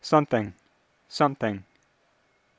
Transcription Practice:  English Dialects and Allophones